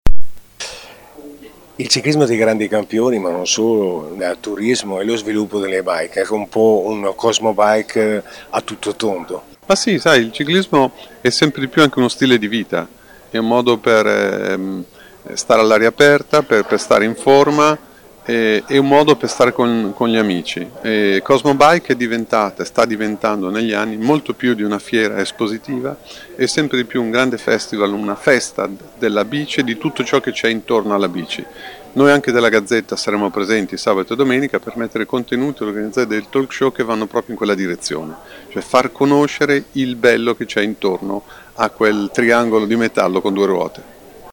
Interviste a cura del corrispondente